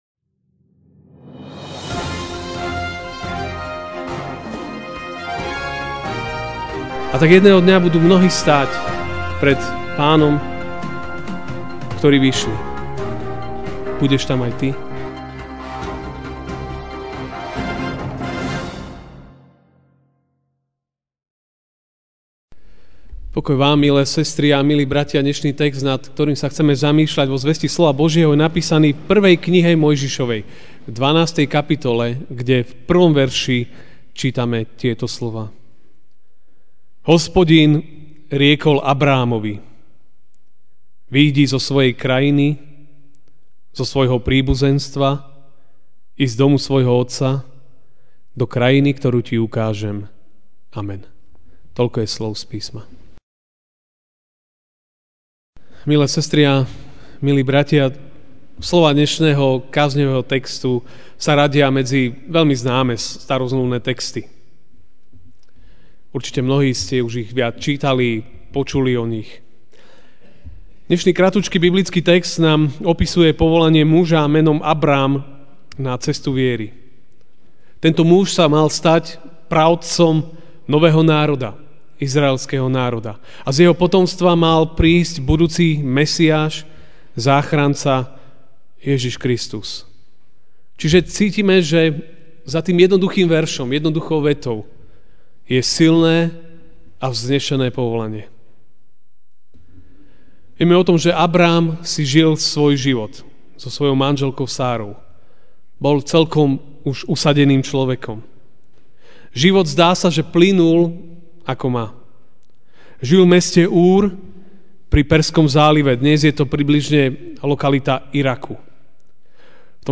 MP3 SUBSCRIBE on iTunes(Podcast) Notes Sermons in this Series Ranná kázeň: Vyjdi!